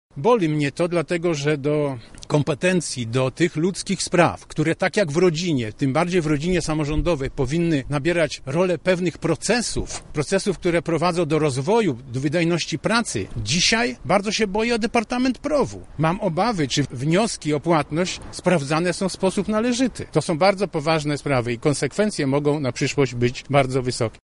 Inny radny, a zarazem były marszałek, Sławomir Sosnowski, złożył dodatkowo wniosek do przewodniczącego sejmiku w sprawie częstych zmian na dyrektorskich stanowiskach w różnych departamentach urzędu marszałkowskiego: